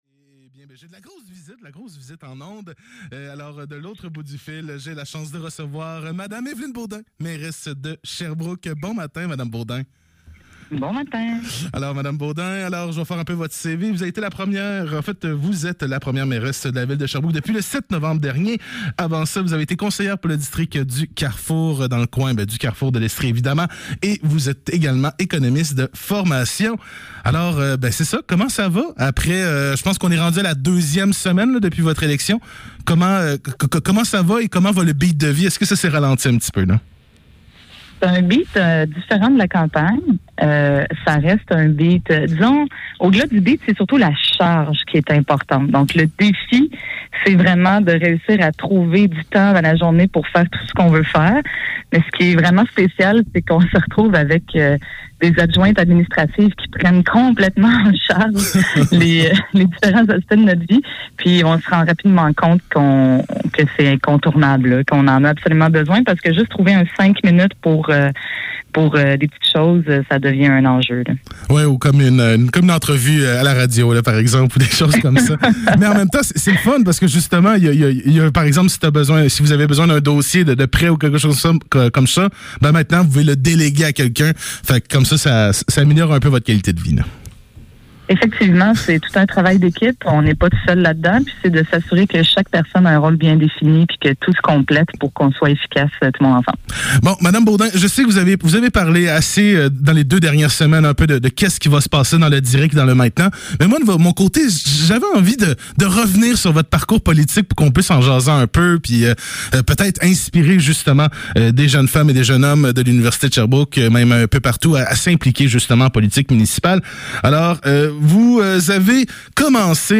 Le Sept - 22 novembre 2021 - Entrevue avec la mairesse de Sherbrooke Évelyne Beaudin sur son parcours politique